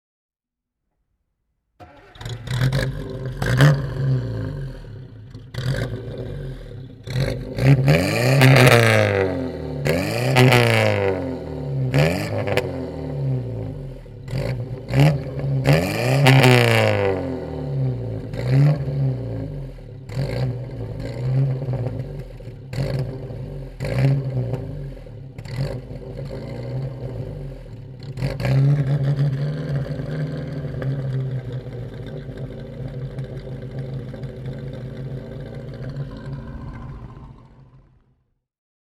Fiat 1100 B Cabriolet (1949) - Starten und Leerlauf